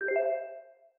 Correct Answer Bling 2.ogg